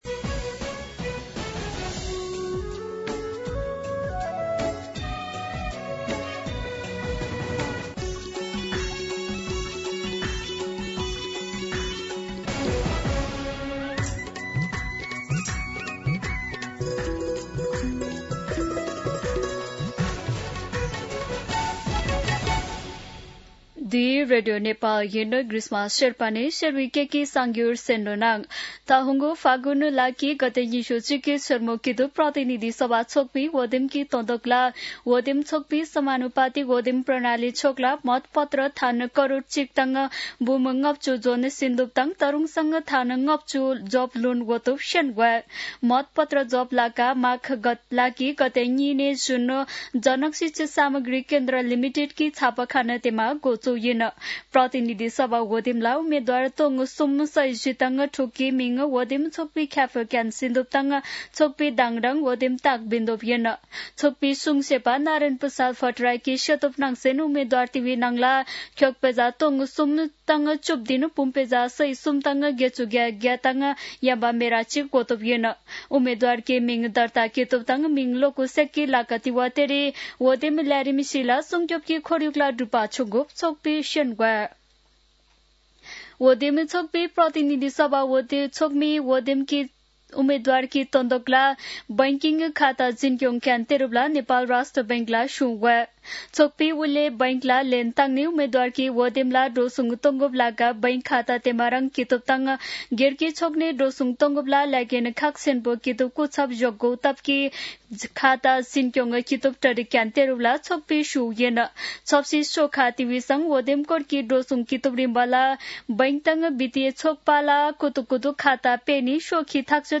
शेर्पा भाषाको समाचार : १० माघ , २०८२